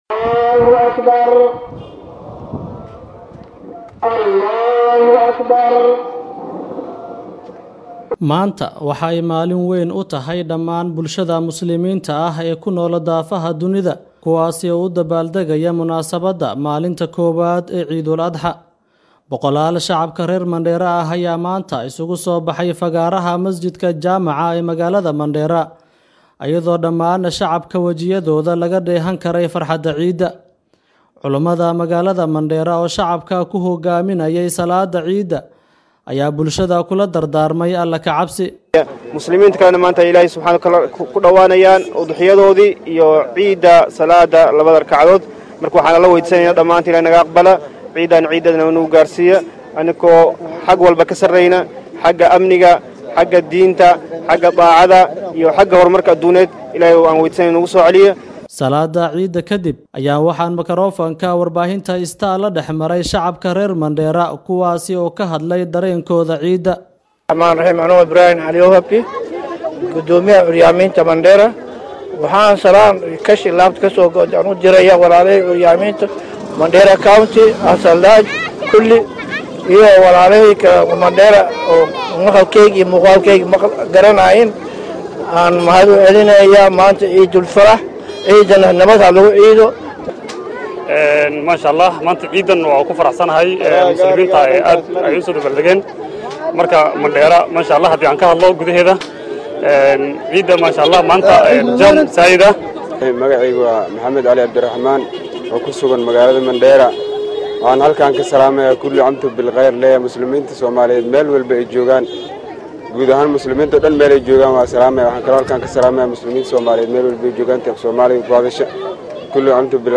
Dadweynaha maanta ku ciiday magaalada Mandera ayaa dareenkooda la wadaagay warbaahinta Star